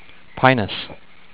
japanese_black_pine.au